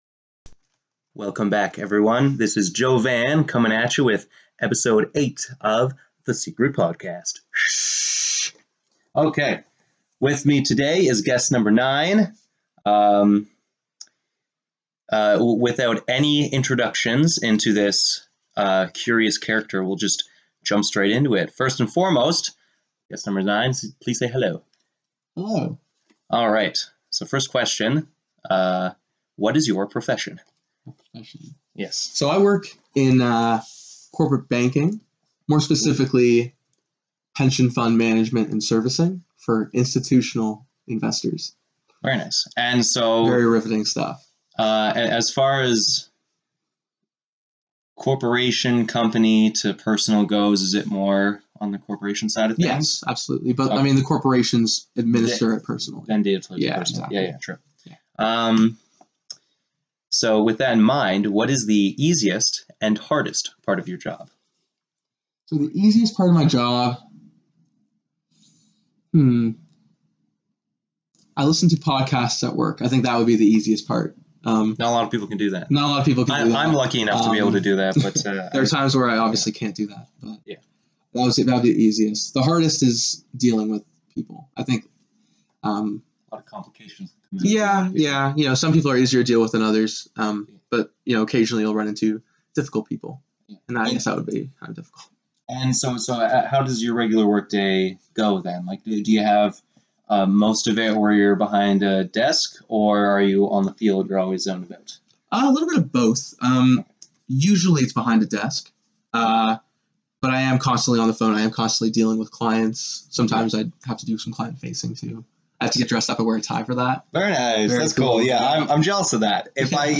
I interview a man, a legend, a dream.